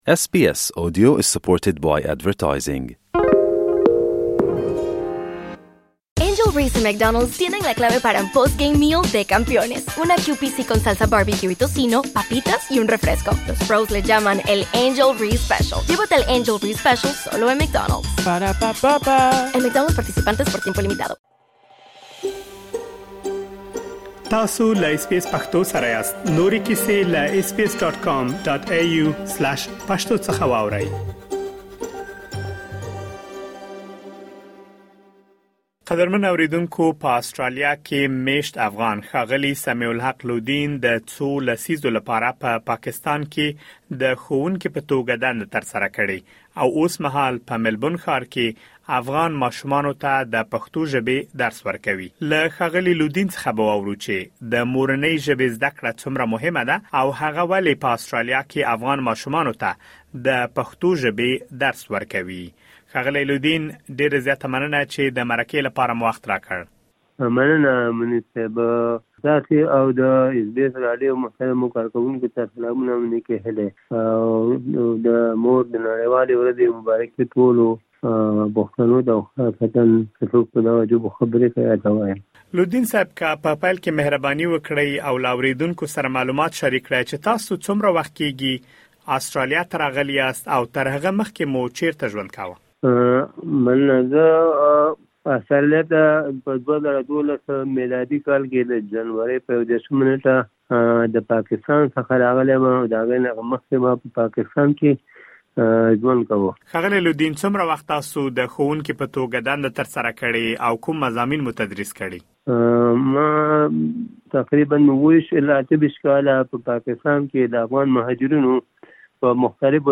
لا ډېر معلومات په ترسره شوې مرکې کې اورېدلی شئ.